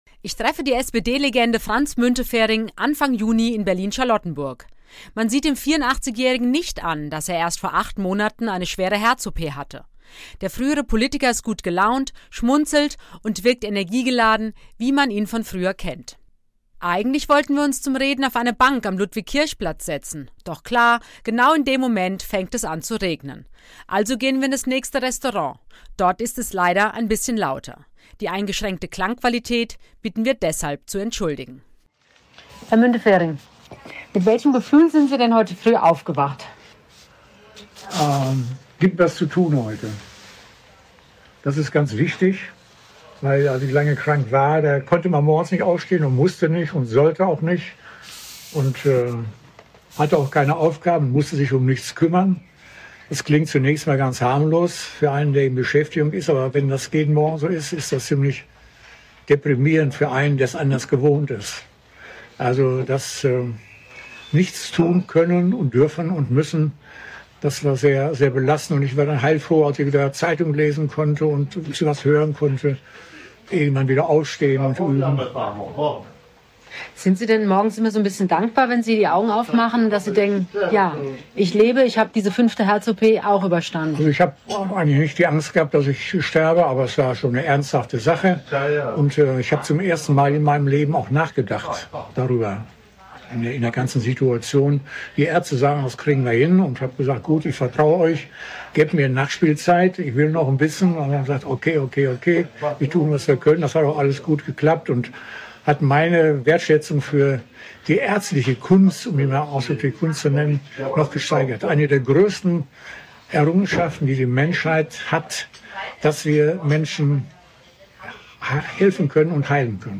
trifft ihn in Berlin-Charlottenburg zum ausführlichen Talk.